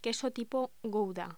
Locución: Queso tipo gouda
Sonidos: Voz humana